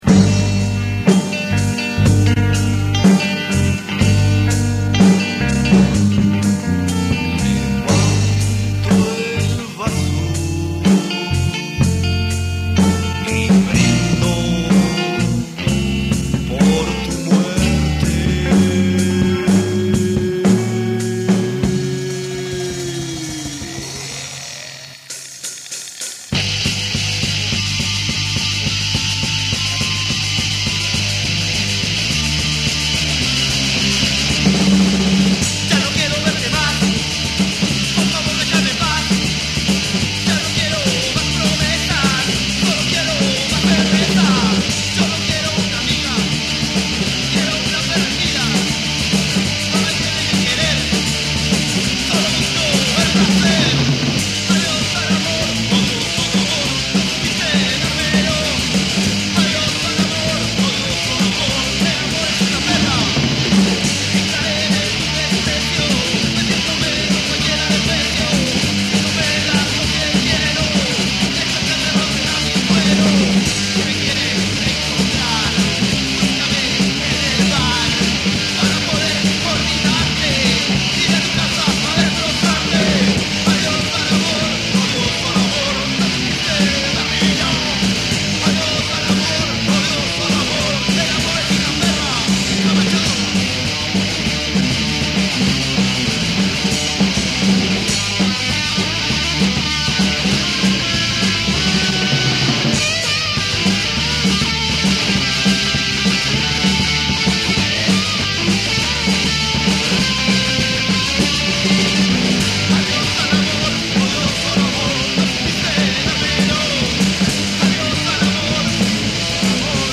#: grabación en multitracker (4 pistas)
punk neto